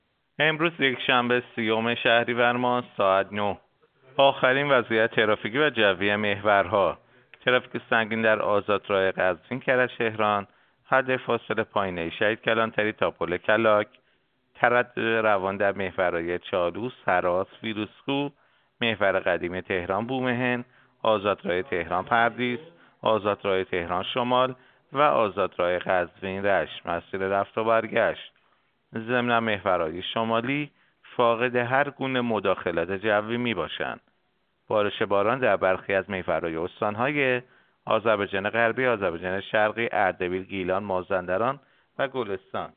گزارش رادیو اینترنتی از آخرین وضعیت ترافیکی جاده‌ها ساعت ۹ سی‌ام شهریور؛